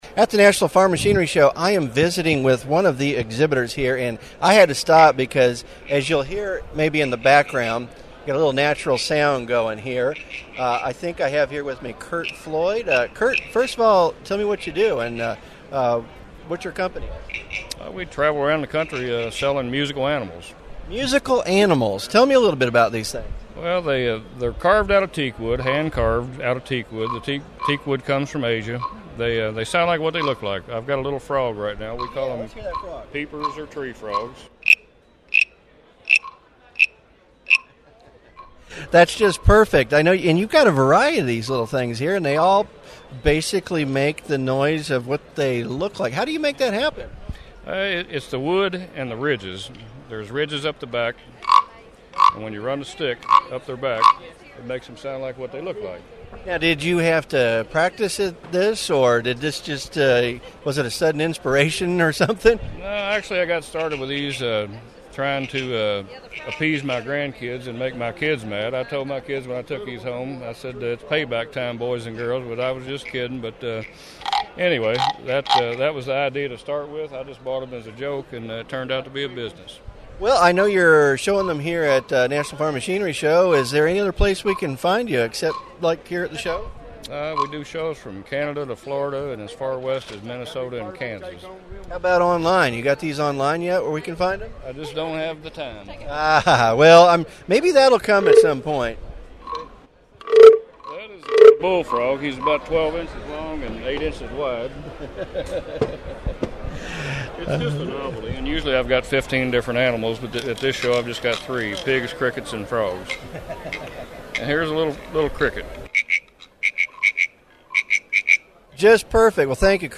At this show he’s got crickets, pigs and frogs and you can hear what they sound like in our interview. He carves them out of imported teakwood and they sound like what they look like if you stroke them with a wooden stick.
2012 National Farm Machinery Show Photo Album